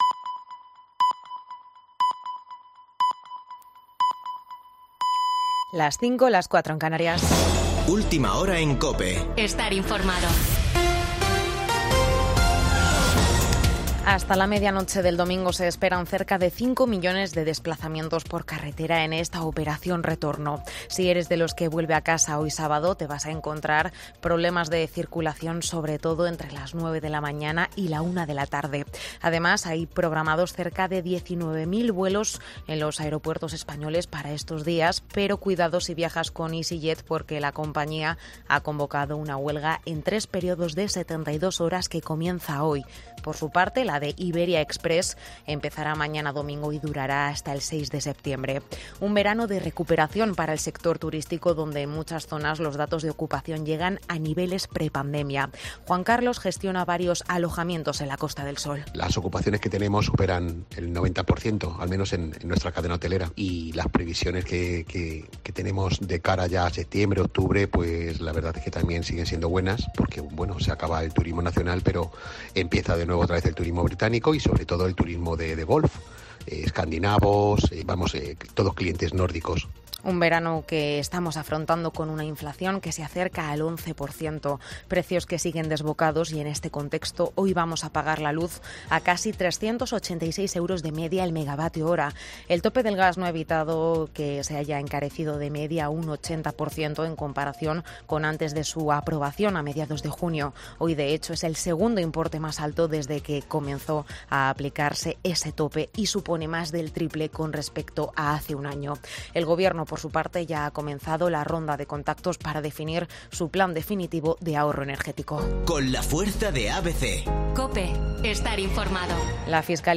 Boletín de noticias de COPE del 27 de agosto de 2022 a las 05.00 horas